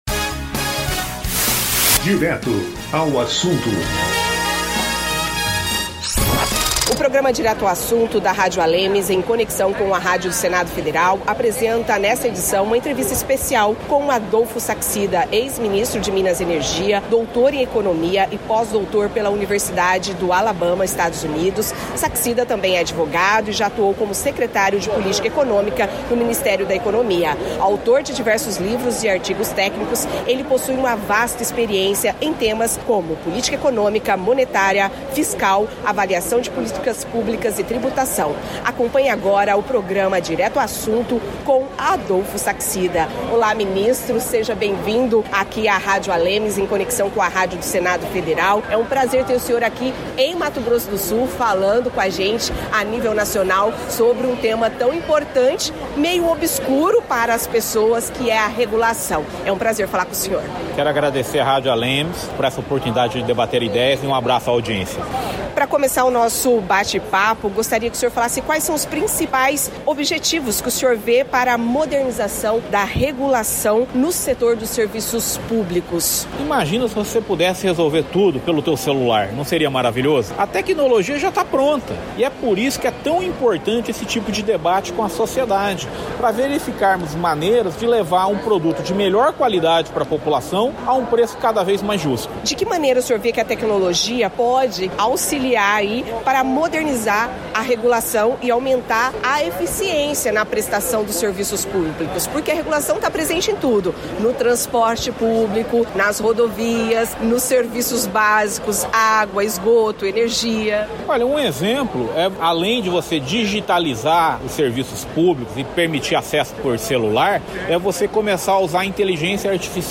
Ex-ministro de Minas e Energia, Adolfo Sachsida, é o entrevistado desta edição do Direto Assunto
O programa Direto ao Assunto, da Rádio ALEMS, apresenta nesta edição uma entrevista especial com Adolfo Sachsida, ex-ministro de Minas e Energia.